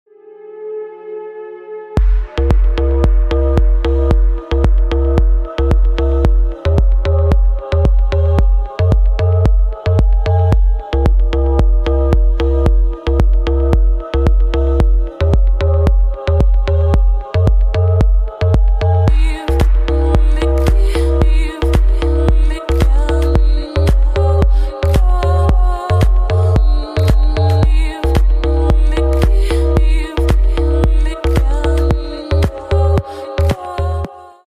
Скачать припев песни